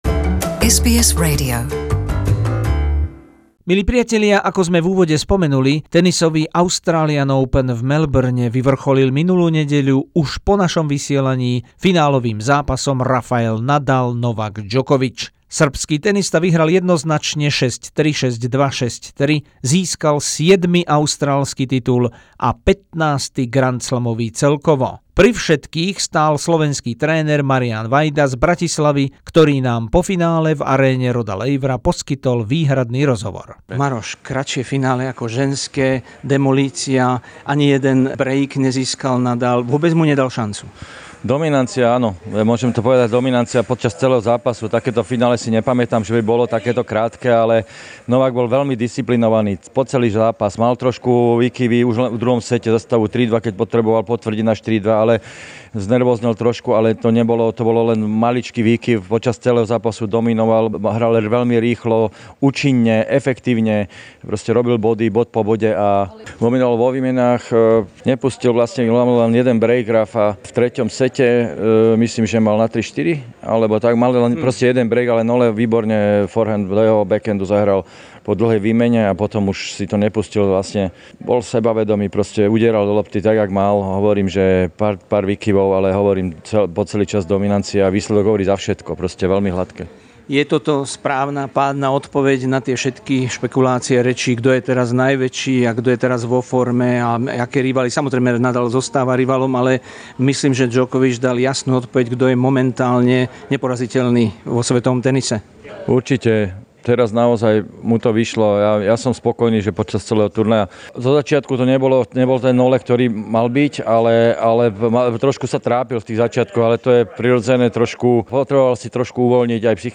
Exclusive SBS interview with the Slovak tennis legend Marian Vajda, coach of Novak Djokovic, Serbian number one in the world after the final win against Rafael Nadal at the 2019 Australian Open in Melbourne.